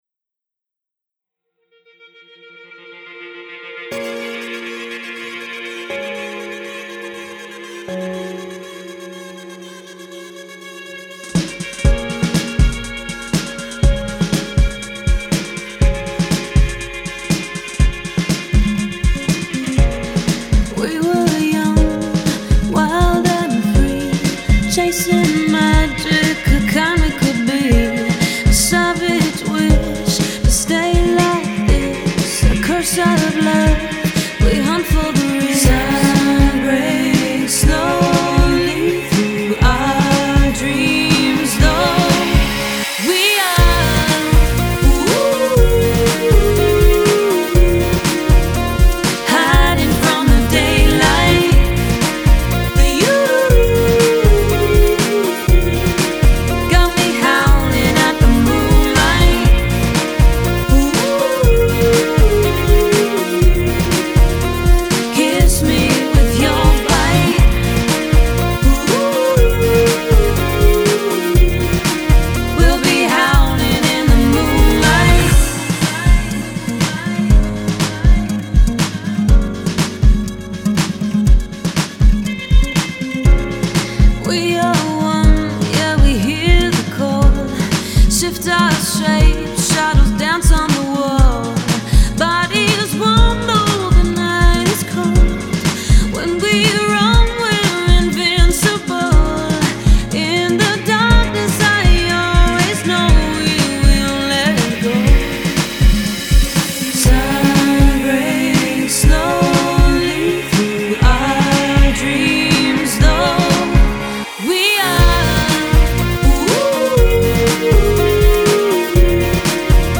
I have a 2nd mix with fewer delays attached.